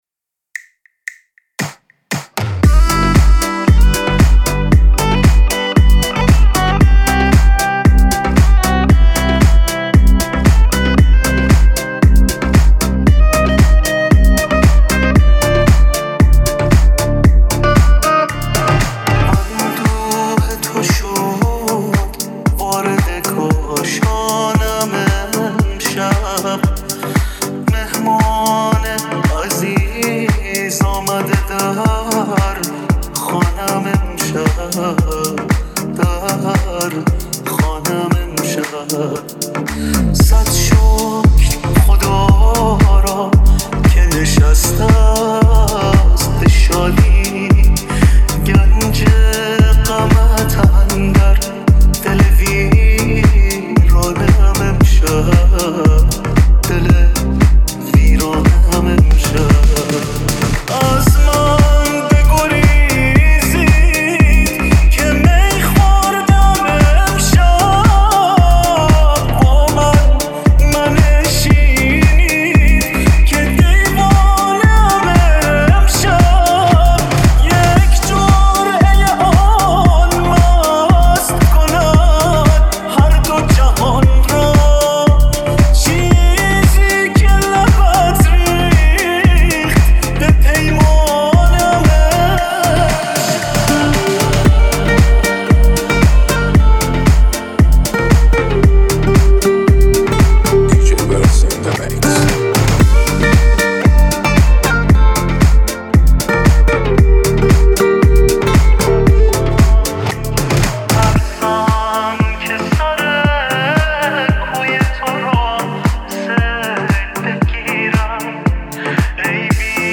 با صدای گرم